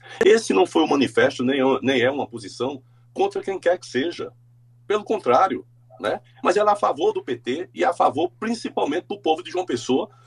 O comentário de Coutinho foi registrado pelo programa Correio Debate, da 98 FM, de João Pessoa, nesta segunda-feira (08/04).